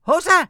traf_oops2.wav